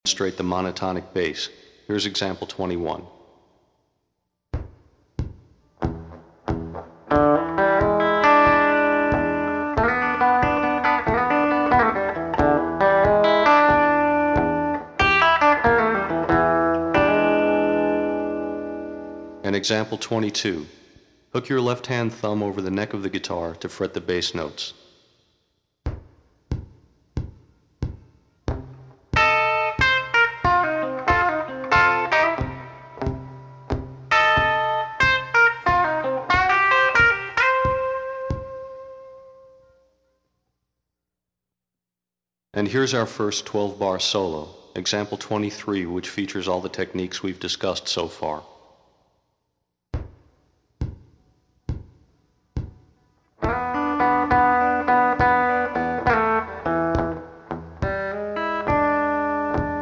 Contains a wealth of great licks and phrases.